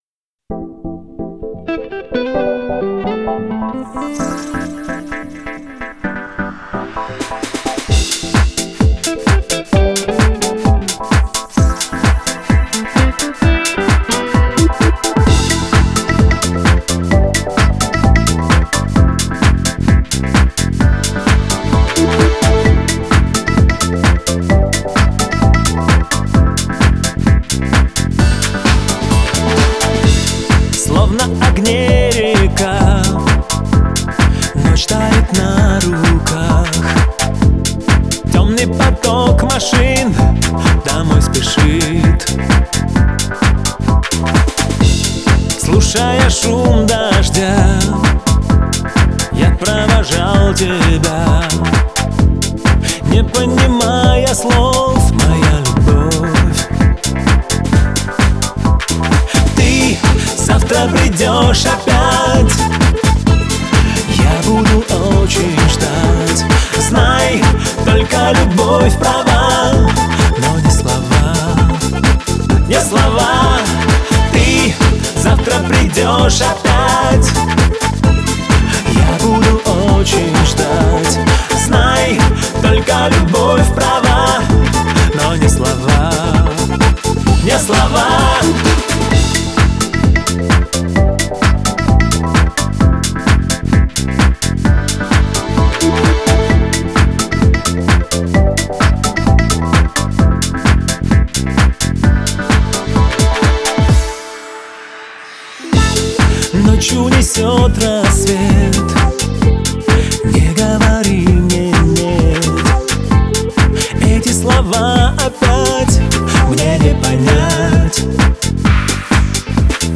это трек в жанре поп с элементами фолка
его голос наполнен теплотой и страстью.